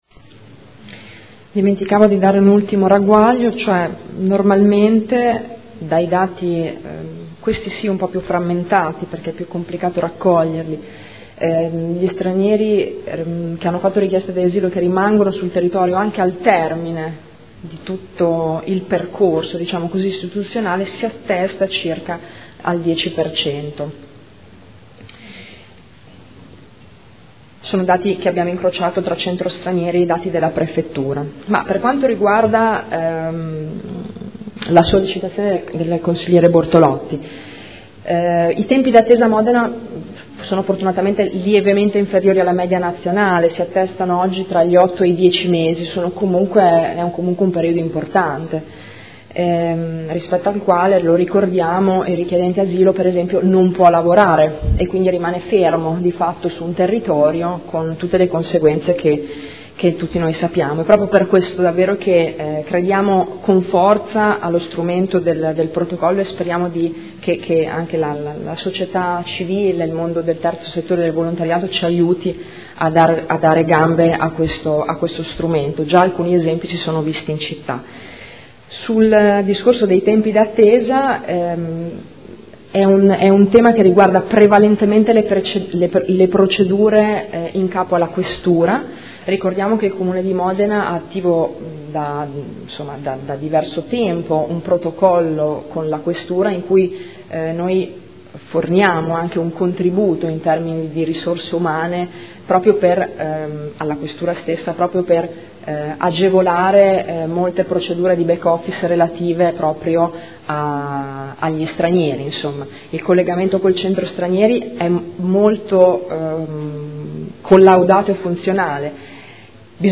Giuliana Urbelli — Sito Audio Consiglio Comunale